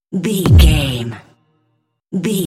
Cinematic stab hit trailer
Sound Effects
Atonal
heavy
intense
dark
aggressive
hits